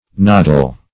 Noddle \Nod"dle\, n. [OE. nodil, nodle; perh. fr. nod, because